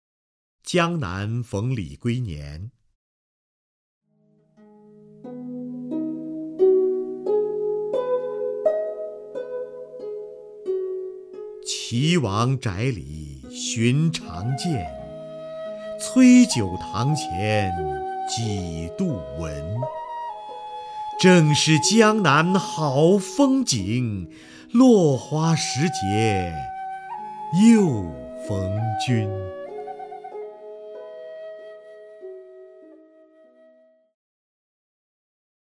瞿弦和朗诵：《江南逢李龟年》(（唐）杜甫) (右击另存下载) 岐王宅里寻常见， 崔九堂前几度闻。